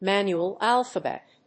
アクセントmánual álphabet